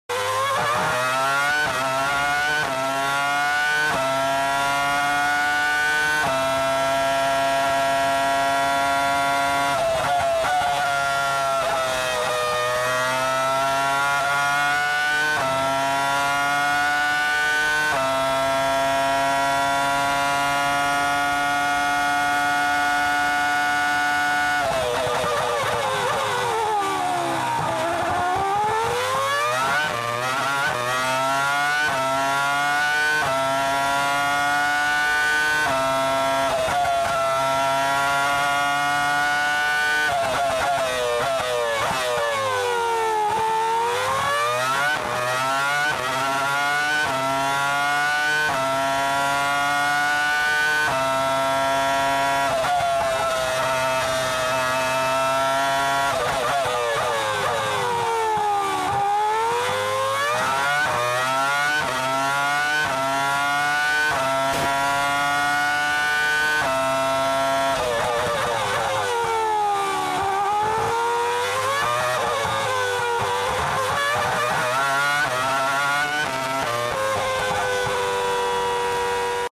Sit and Enjoy The sound of naturally aspirated V10 🔊 One of The Best sounding F1 engine ever !